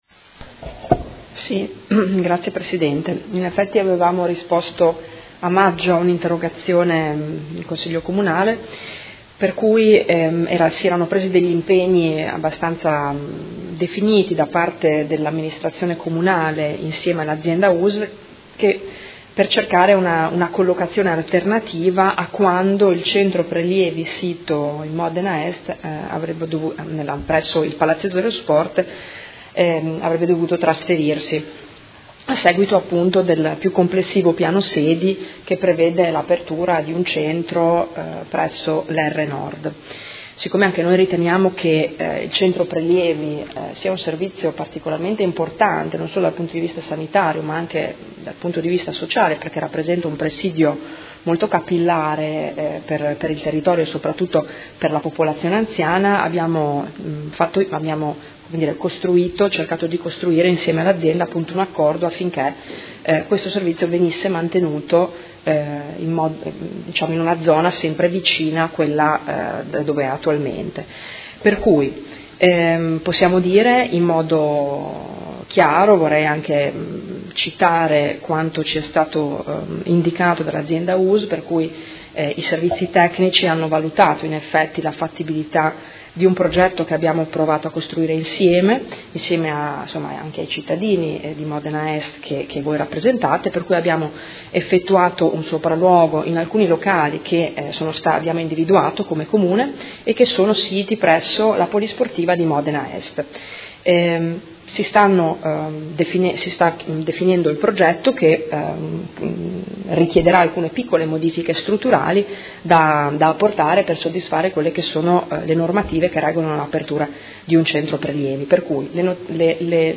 Seduta del 26/03/2018 Risponde. Interrogazione del Consigliere Carpentieri (PD) avente per oggetto: Punto prelievi Modena est